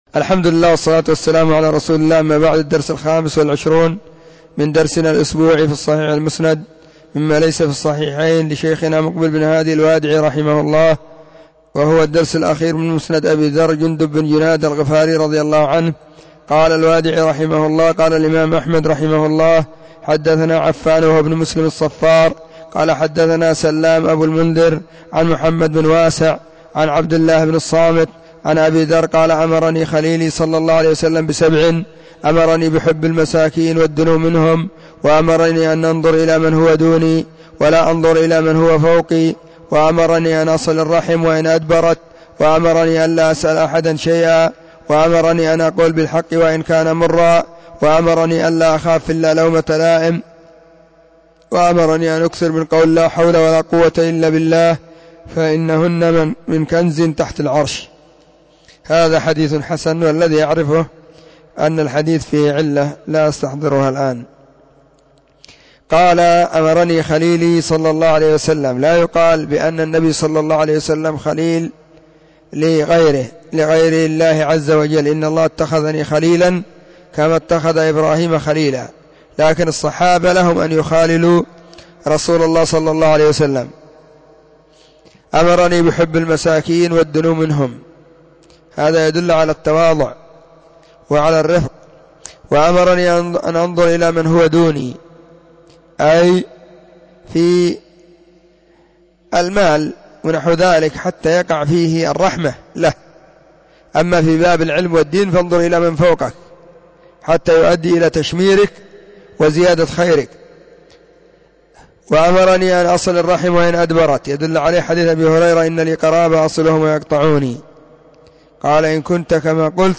خميس -} 📢مسجد الصحابة – بالغيضة – المهرة، اليمن حرسها الله.